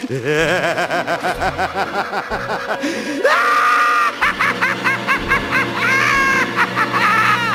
Звуки смеха Джокера
Вы можете слушать онлайн или скачать различные вариации его жуткого, пронзительного и безумного хохота в хорошем качестве.